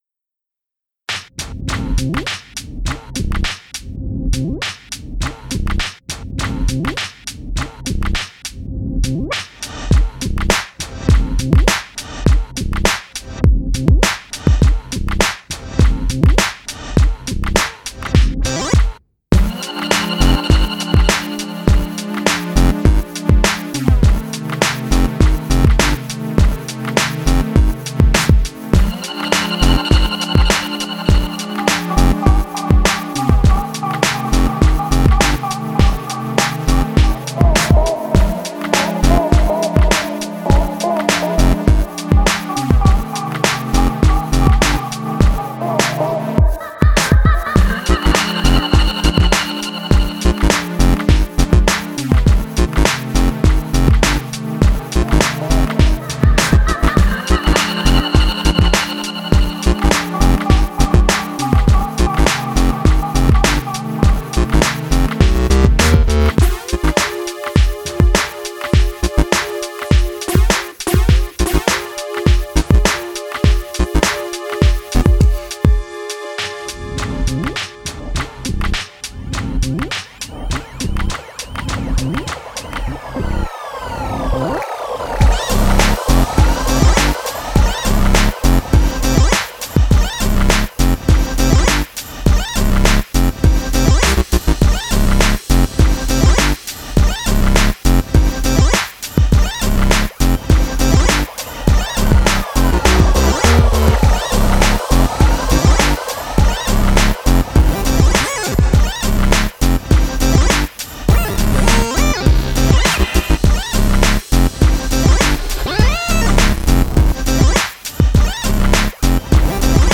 Pumping quirky 8bit beat with attitude and determination.